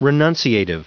Prononciation du mot renunciative en anglais (fichier audio)
Prononciation du mot : renunciative